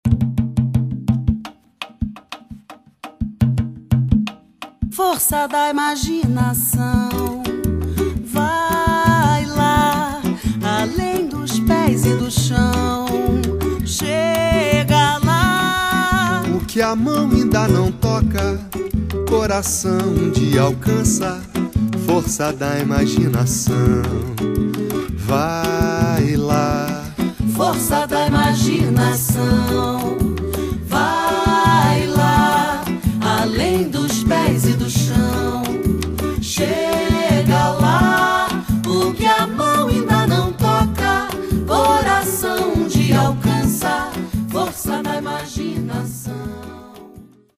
Sensuous samba, bossa nova and jazz from Rio de Janeiro, S